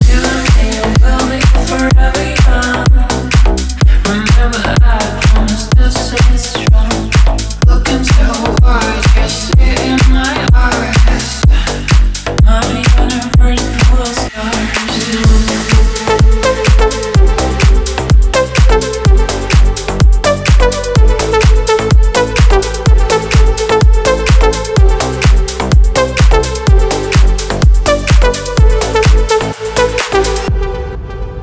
• Качество: 320, Stereo
красивый женский голос
клубняк
Стиль: deep house